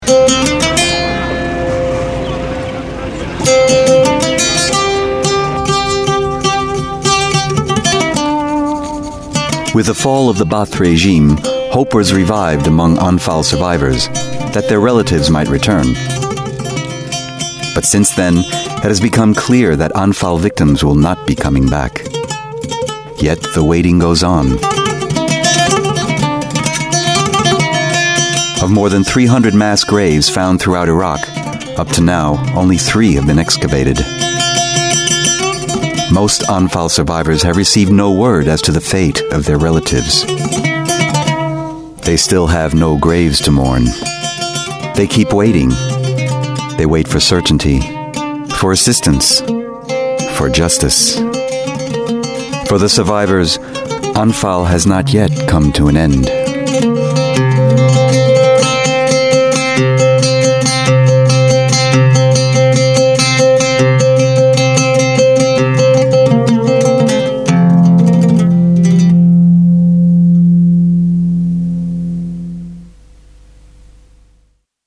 englisch (us)
Sprechprobe: eLearning (Muttersprache):